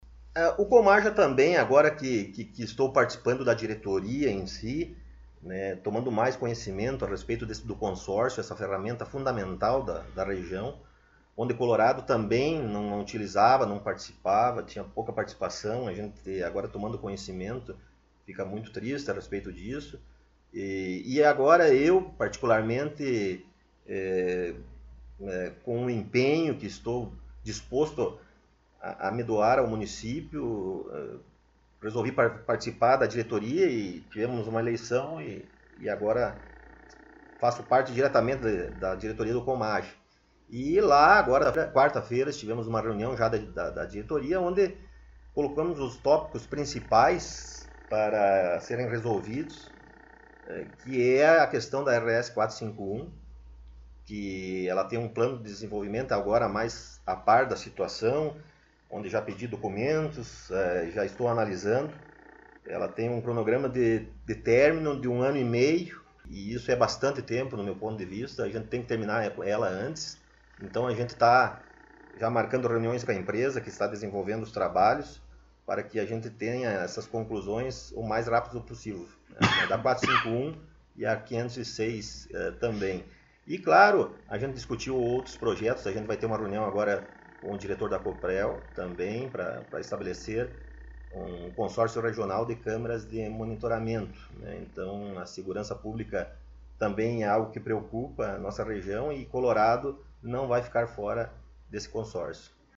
O Colorado em Foco esteve entrevistando na última sexta-feira ( 17 ), no gabinete da Prefeitura Municipal o prefeito Rodrigo Sartori.